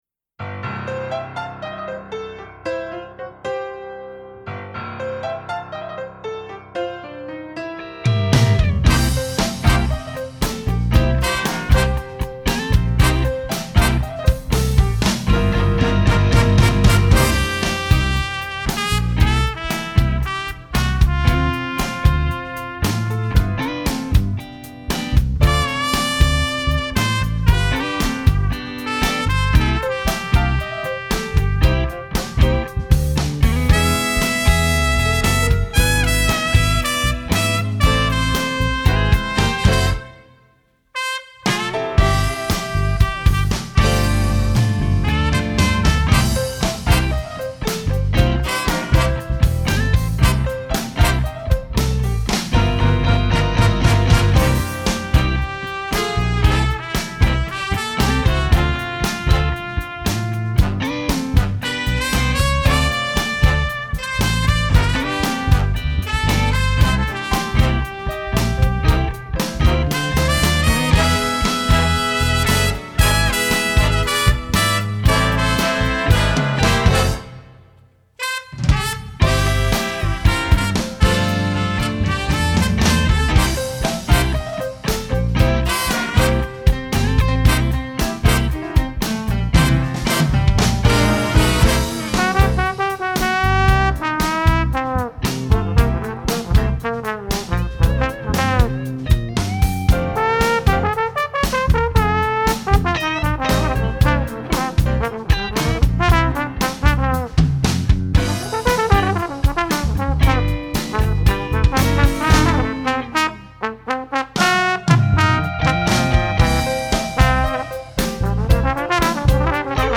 Trumpet 1 & 2
Trombone 1 & 2
Tenor Sax
Written out piano/keyboard
Written out electric bass